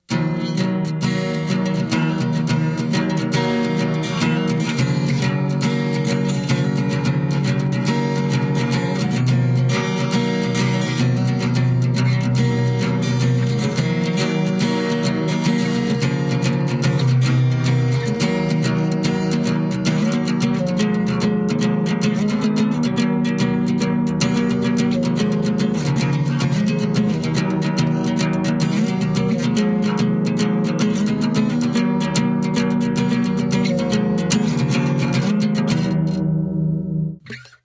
Сталкеры как вам песня что думайте, кто помнит)?
Напоминает музыку из меню первых Народных солянок , только эта побыстрее
guitar_2.mp3